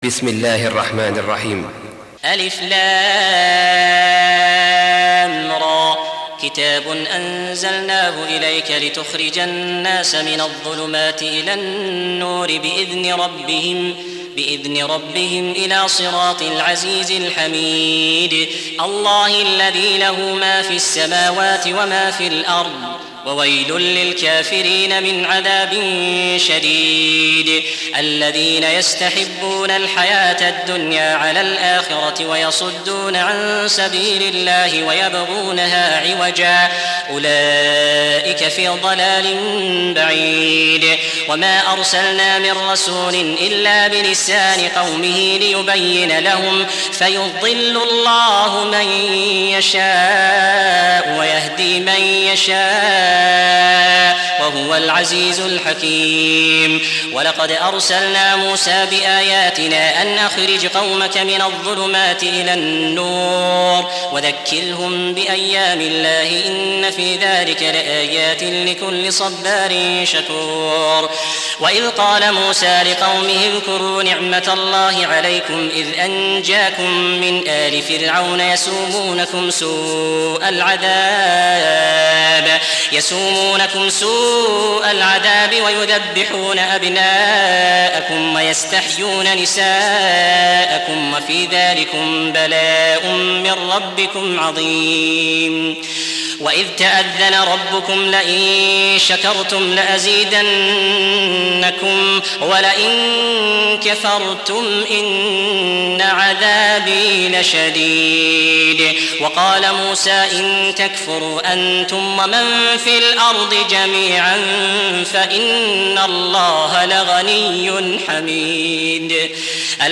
Surah Repeating تكرار السورة Download Surah حمّل السورة Reciting Murattalah Audio for 14. Surah Ibrah�m سورة إبراهيم N.B *Surah Includes Al-Basmalah Reciters Sequents تتابع التلاوات Reciters Repeats تكرار التلاوات